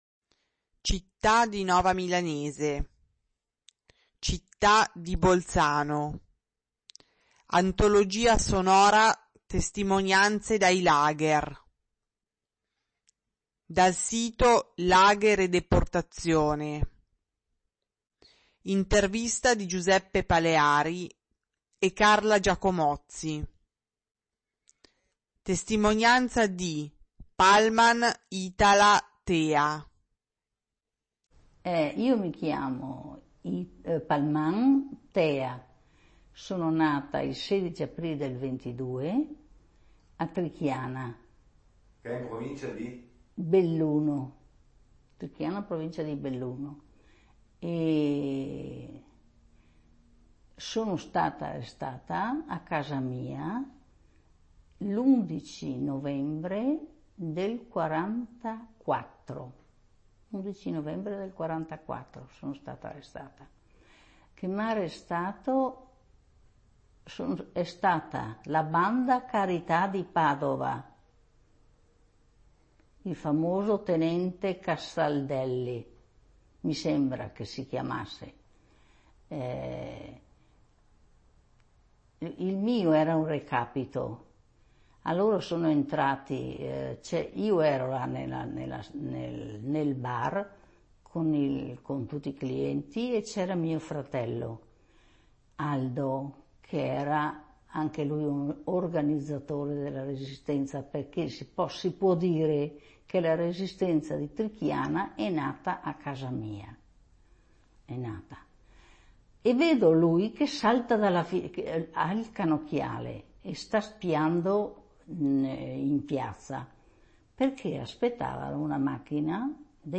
Intervista del